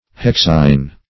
Search Result for " hexine" : The Collaborative International Dictionary of English v.0.48: Hexine \Hex"ine\, n. [Gr.